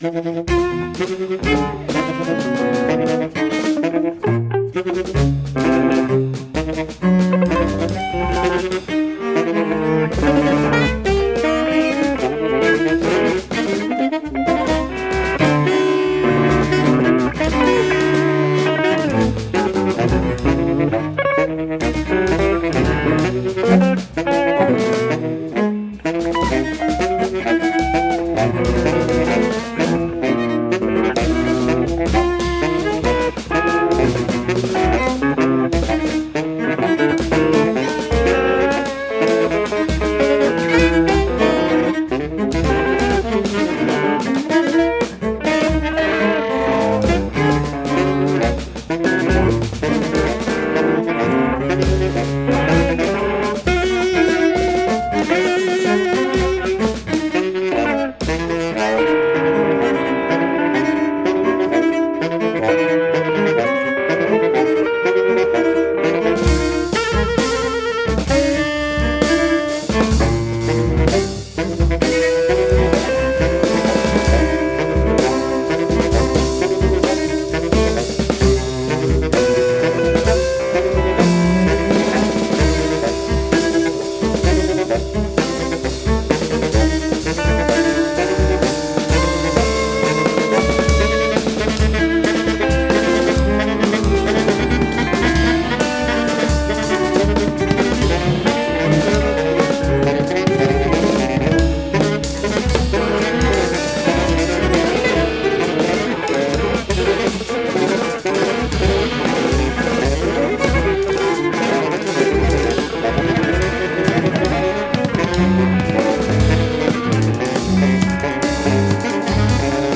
electric guitar
tenor saxophone
cello
percussion
Recorded in Cologne at Topaz Studios
this is instrumental music.